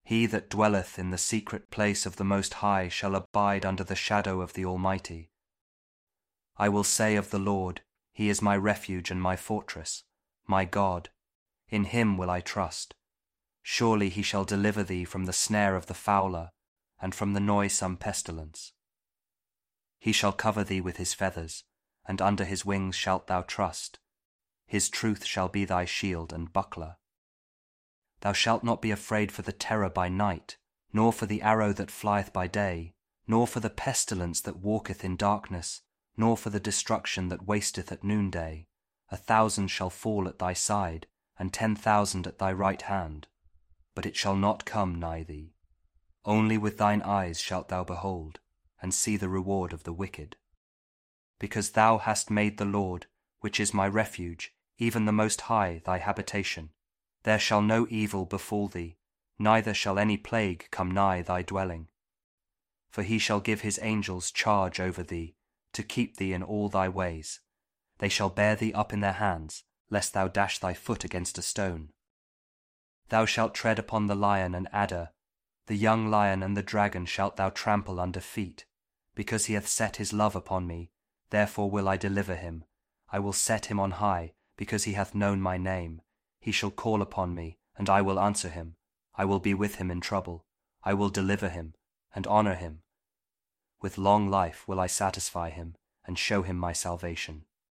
Psalm 91 | KJV | King James Version | Audio Bible